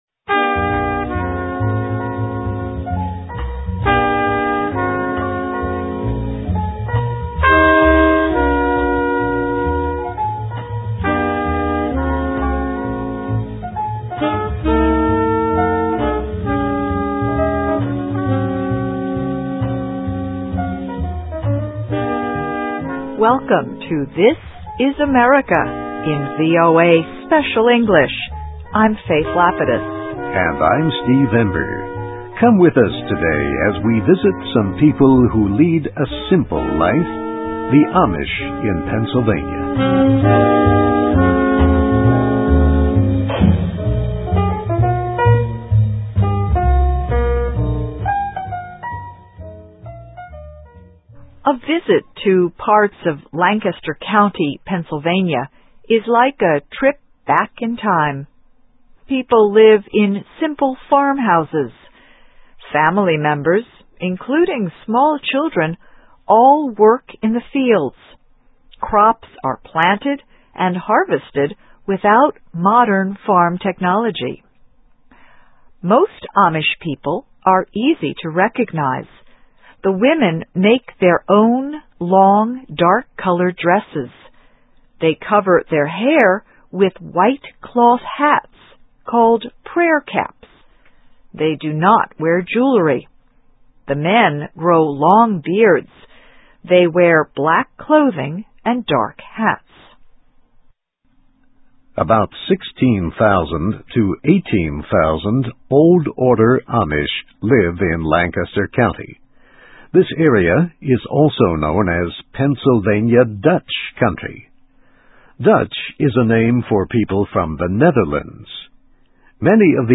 USA: Living a Simple Life: the Amish of Lancaster County, Pennsylvania (VOA Special English 2005-05-01)<meta name="description" content="Text and MP3 File.
Listen and Read Along - Text with Audio - For ESL Students - For Learning English